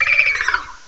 cry_not_wishiwashi.aif